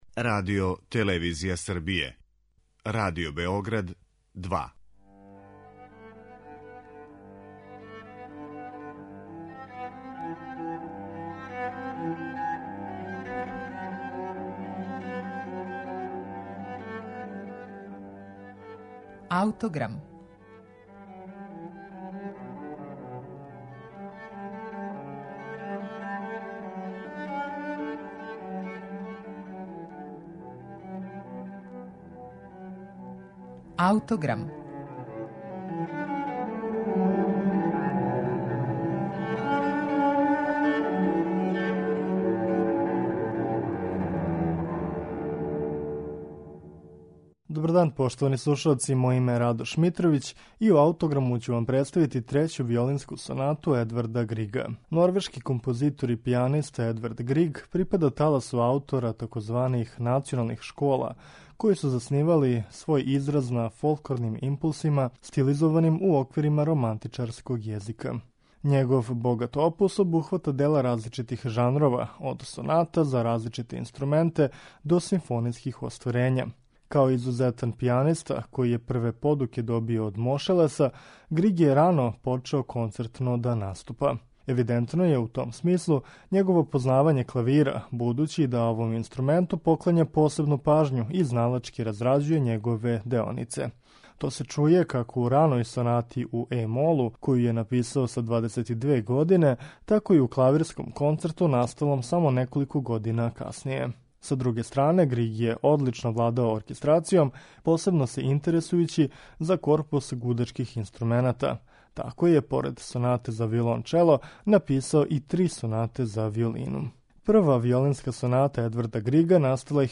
Слушаћете Трећу виолинску сонату Едварда Грига
Слушаћете је у извођењу Аугустина Думаја и Марије Жоао Пиреш.